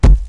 Collision0001.ogg